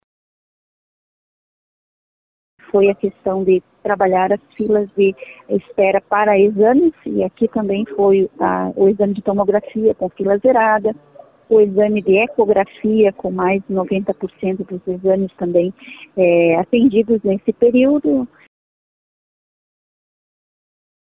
Outro ponto destacado pela secretária foi a redução das filas para exames, com a queda no tempo médio de 60 para 49 dias.
Tatiane Filipak citou exames de tomografia, que estão com filas zeradas no SUS, e ecografia, com baixos índices de espera.